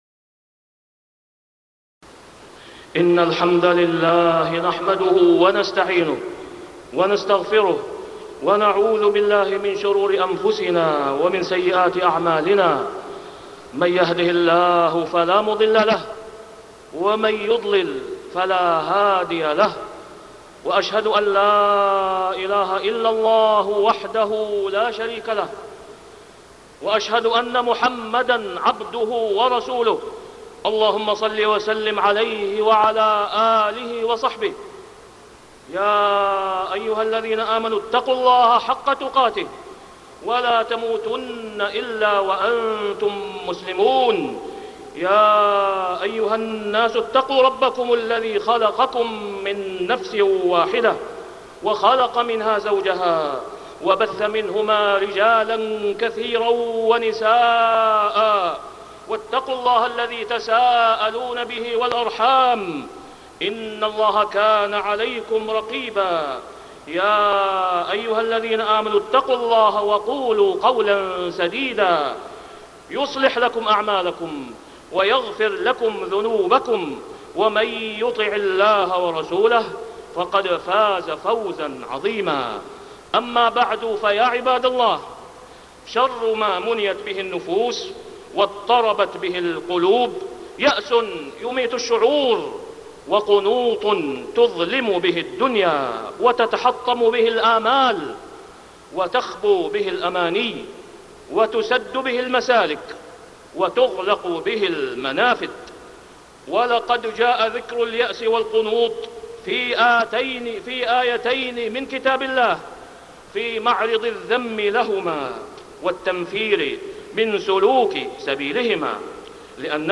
تاريخ النشر ٢٣ جمادى الآخرة ١٤٢٦ هـ المكان: المسجد الحرام الشيخ: فضيلة الشيخ د. أسامة بن عبدالله خياط فضيلة الشيخ د. أسامة بن عبدالله خياط حسن الظن بالله